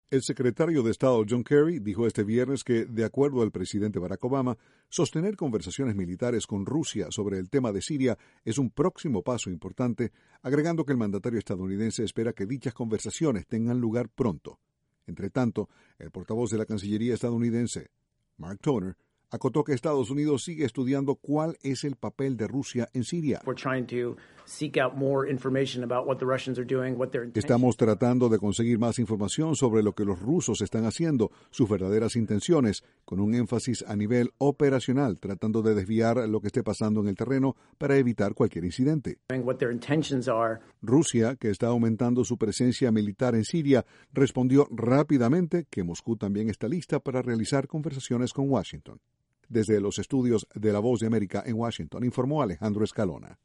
Estados Unidos dijo estar estudiando cuidadosamente lo que Rusia está haciendo en Siria. Desde la Voz de América, Washington, informa